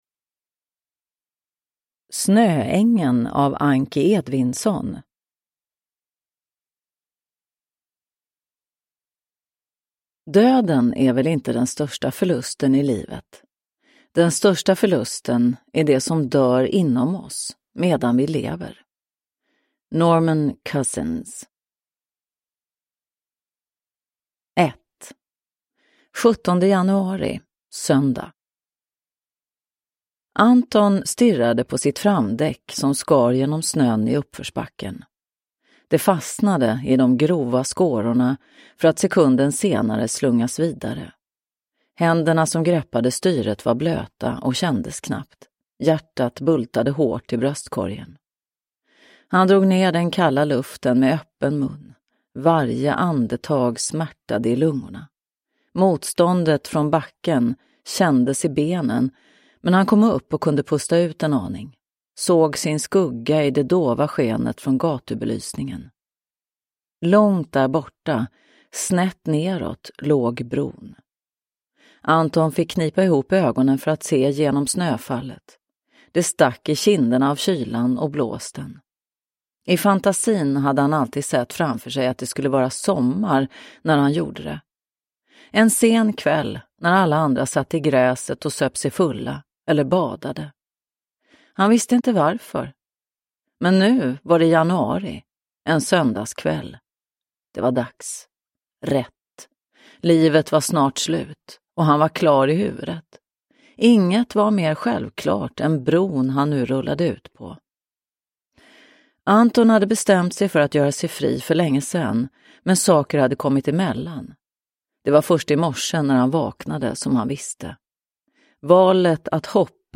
Snöängeln – Ljudbok – Laddas ner